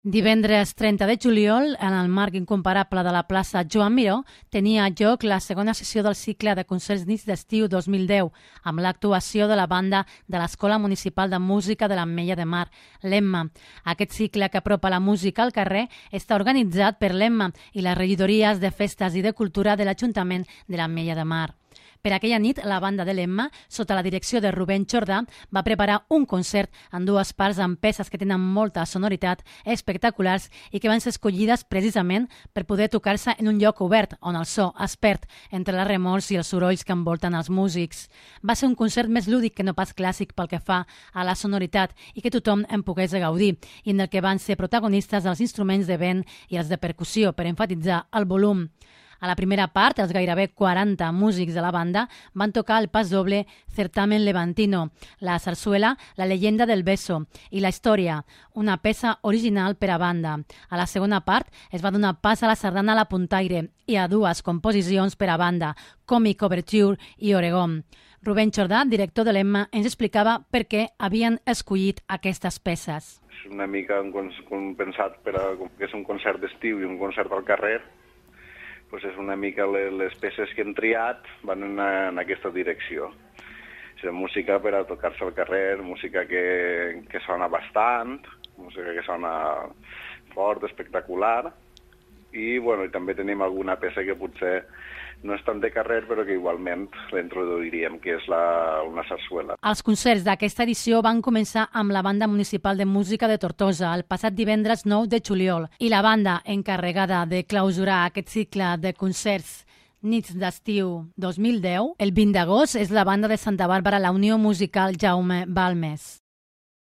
La banda va preparar sis peces que s'adapten perfectament a l'acústica, i el soroll de fons d'un espai obert com és la plaça Joan Miró.